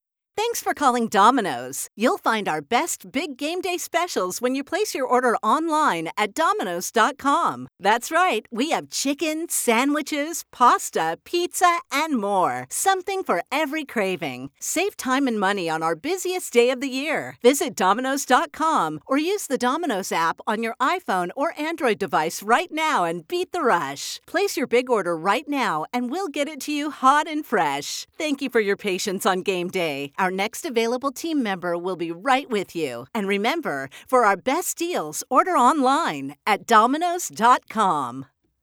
Choose from one of our professionally produced, pre-recorded studio quality messages or customize for your store!
Order in Advance Auto-Attendant Messaging